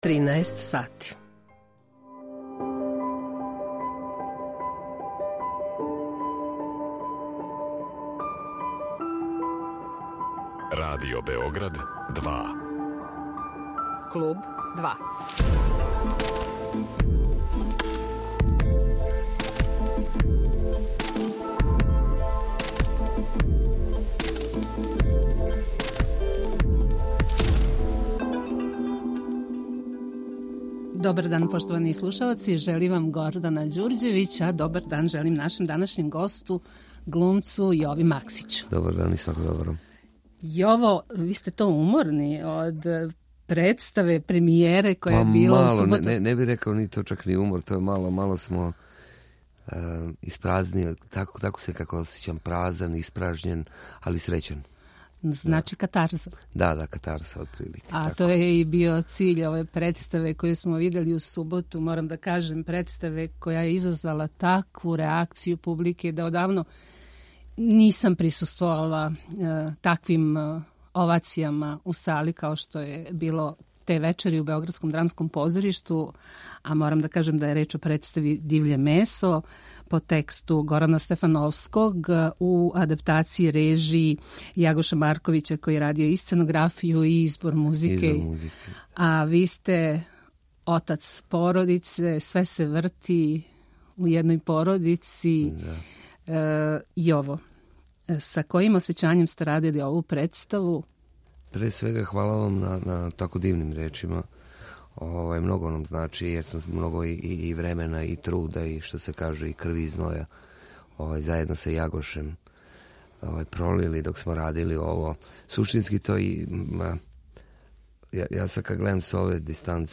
Гост Клуба 2 je један од наших најзначајнијих глумаца ‒ Јово Максић.